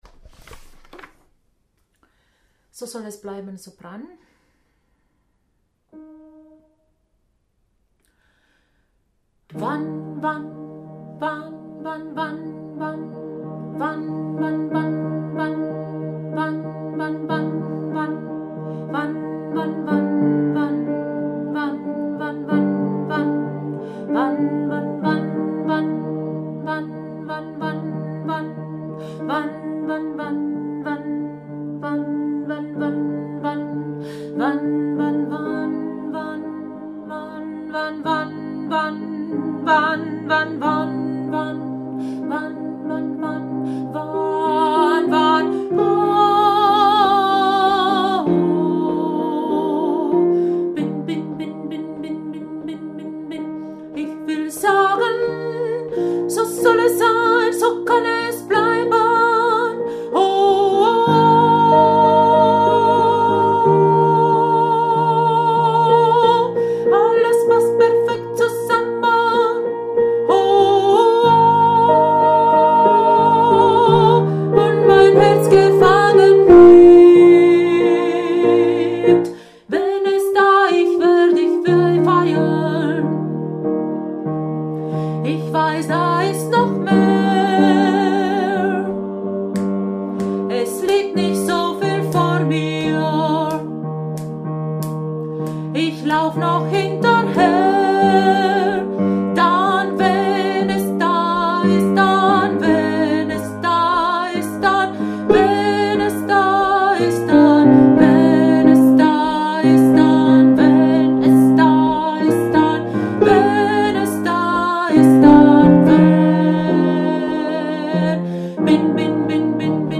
So soll es sein, so kann es bleiben – Sopran
So-soll-es-sein-so-kann-es-bleiben-Sopran.mp3